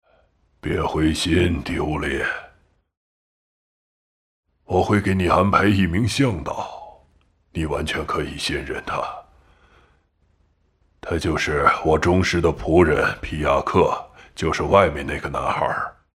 Film & TV VO
“Dubbed Films Compilation – Male” All audio samples below are original excerpts from final soundtracks, featuring male characters across age groups—from childhood to senior years.
70-year-old elder—twin brother of the king, a wise, benevolent man detached from fame and fortune. The voice actor delivers a steady, weathered vocal tone that retains inner strength, creating a deeply trustworthy, sage-like elder portrayal.